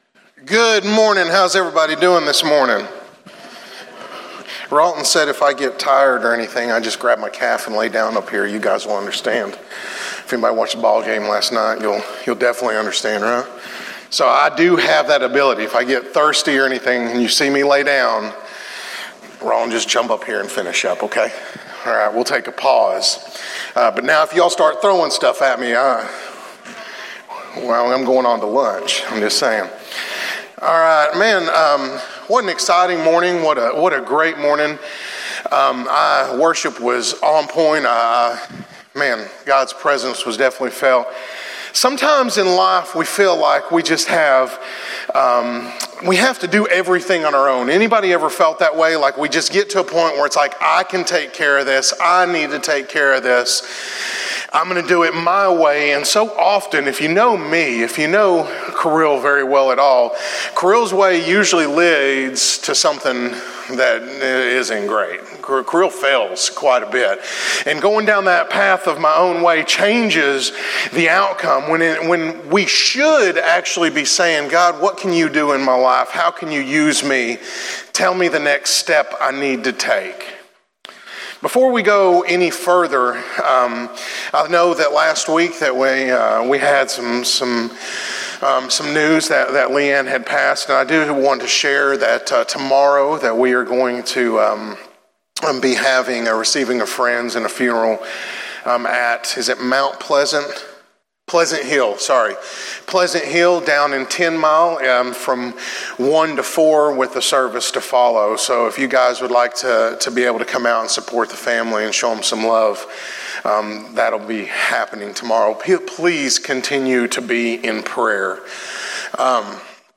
In today's sermon from our series on Genesis, we talk about those times when we feel like we don't measure up, and how we often forget how God can work in our lives despite our own limitations.